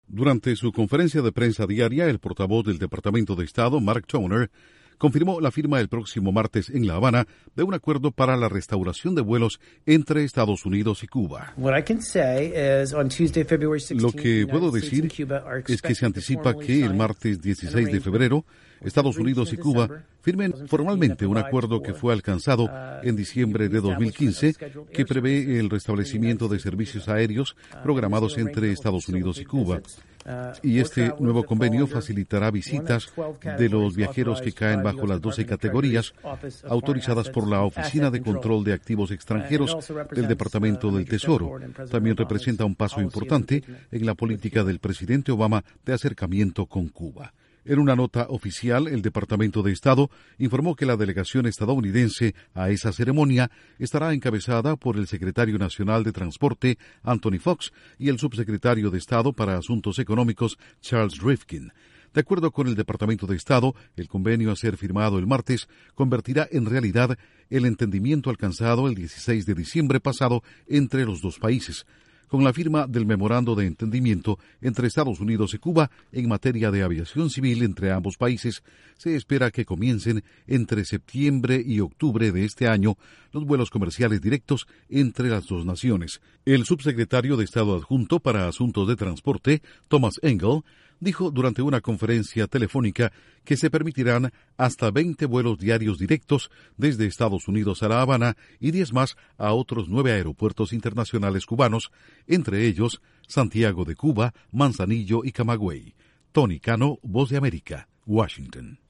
Estados Unidos y Cuba se aprestan a firmar un acuerdo para la restauración de vuelos diarios entre ambos países. Informa desde la Voz de América en Washington